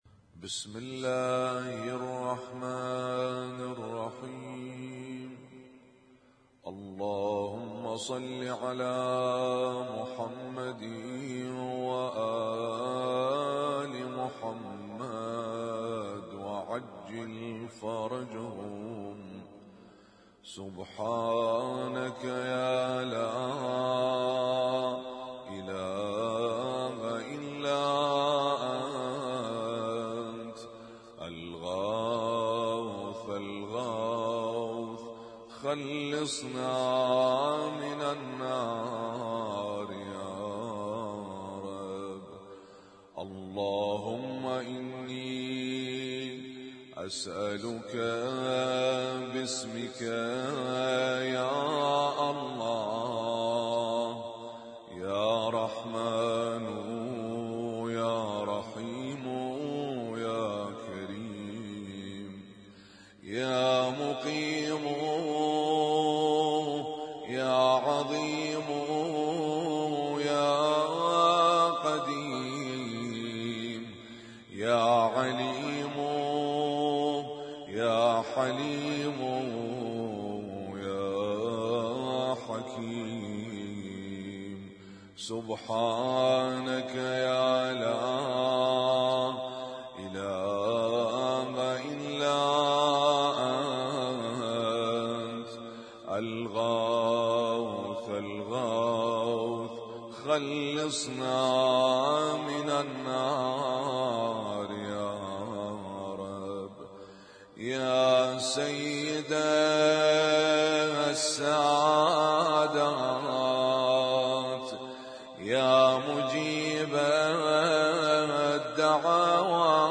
Husainyt Alnoor Rumaithiya Kuwait
اسم التصنيف: المـكتبة الصــوتيه >> الادعية >> الادعية المتنوعة